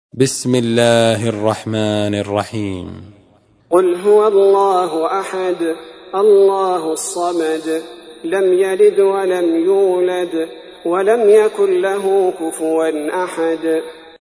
تحميل : 112. سورة الإخلاص / القارئ عبد البارئ الثبيتي / القرآن الكريم / موقع يا حسين